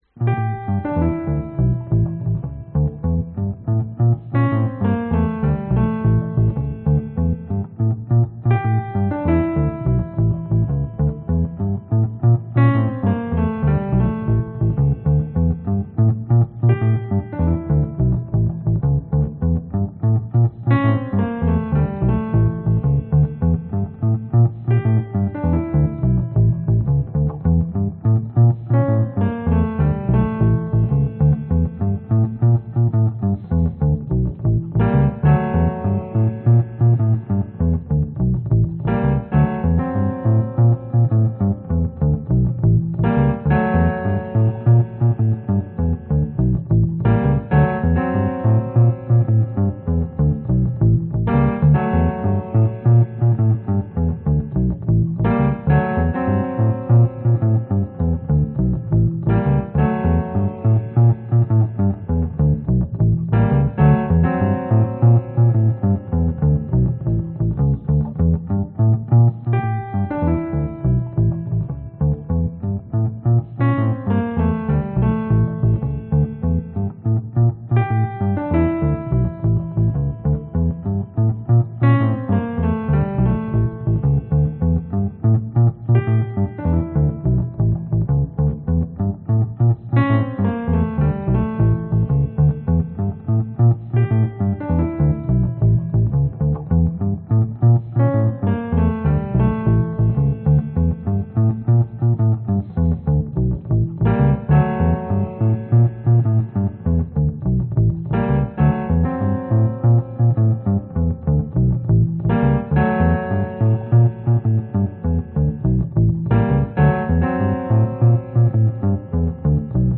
Tag: 器乐 贝司 钢琴 爵士乐 极简主义 商业使用